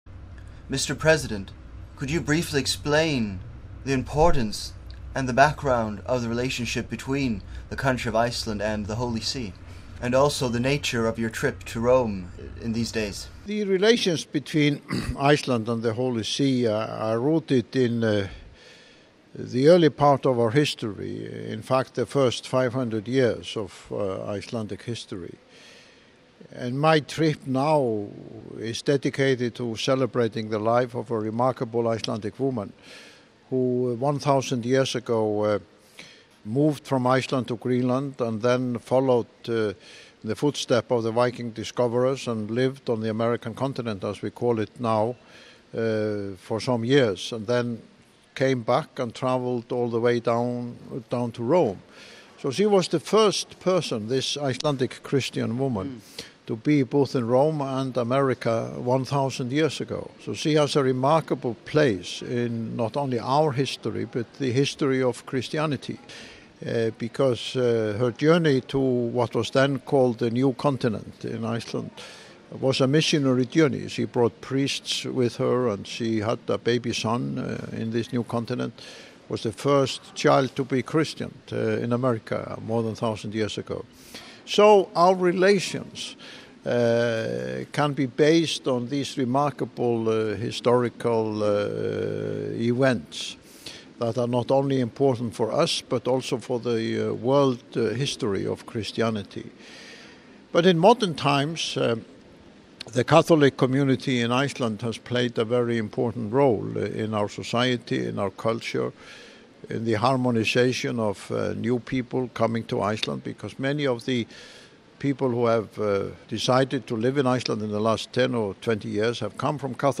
Islands president i intervju med Vatikanradion
(05.03.11) Under en konferens på det påvliga universitetet Angelicum fick Vatikanradion möjlighet att intervjua Islands president Olafur Ragnar Grimsson om Islands relation till den Heliga Stolen och den isländska kristna upptäckresanden Gudrun Torbjörnsdottir.